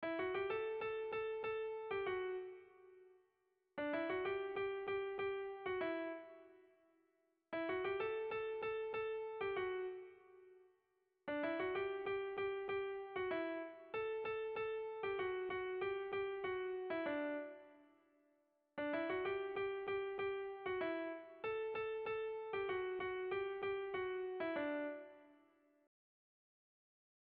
Dantzakoa
Eibar < Debabarrena < Gipuzkoa < Euskal Herria
Trikitia fandango doinu ezagunenetakoa. Errepikapenek grazia berezia dute.